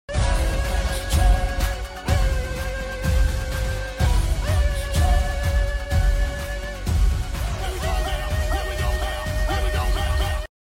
Lizard screaming
Lizard-screaming.mp3